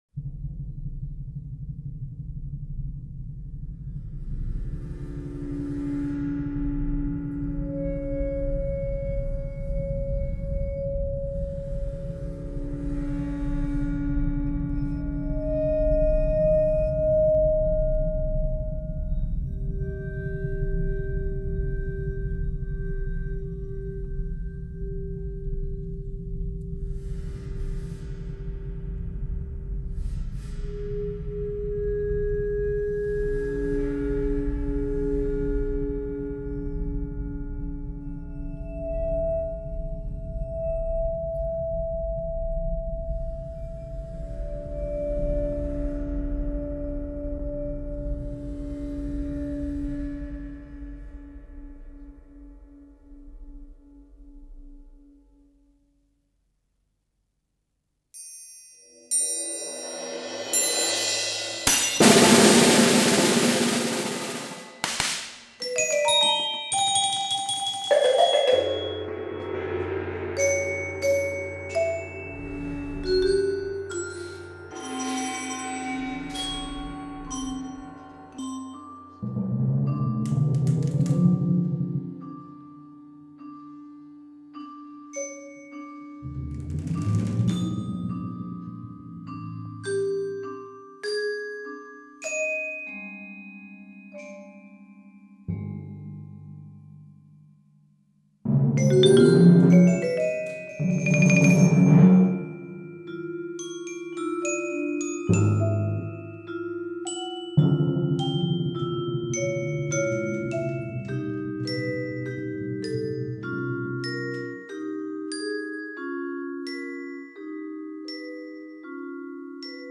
Leftraro para cuarteto de percusiones